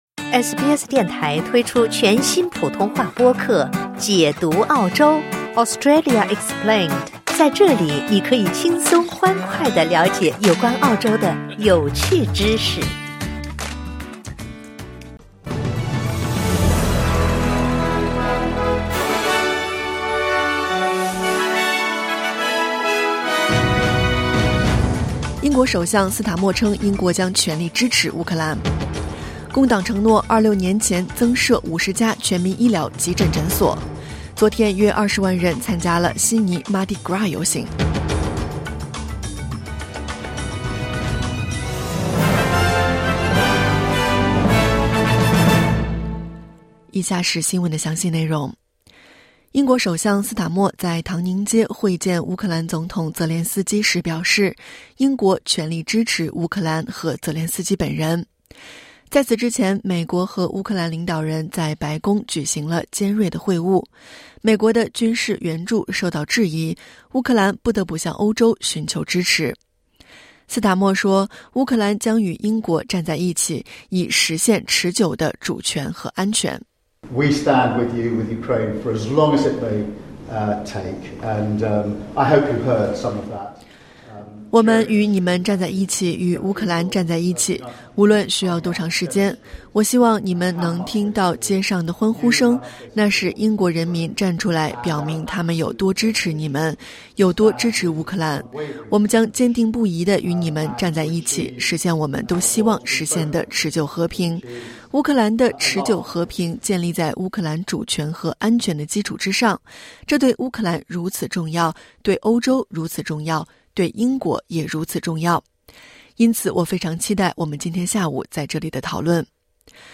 SBS早新闻（2025年3月2日）